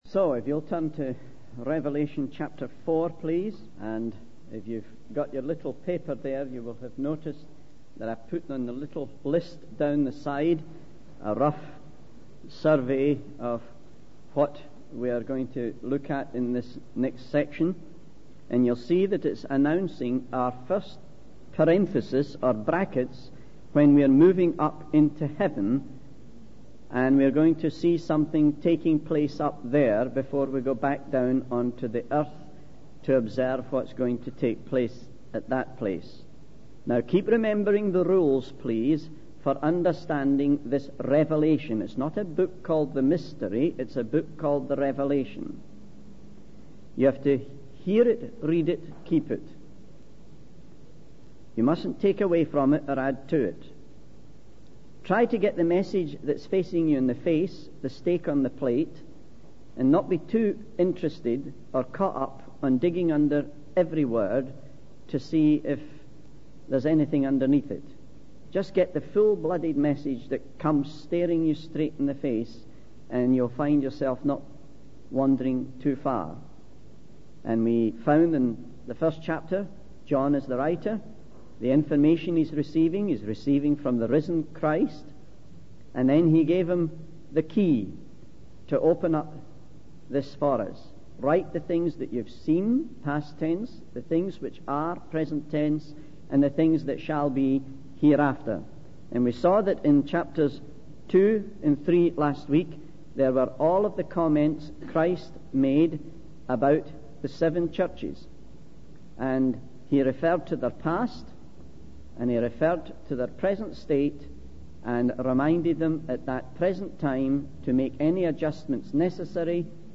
In this sermon, the preacher focuses on the book of Revelation, specifically chapter 4. He emphasizes the importance of understanding that Revelation is a book of revelation, not a mystery. The preacher describes the scene in heaven, where John sees a throne with someone sitting on it, surrounded by a rainbow.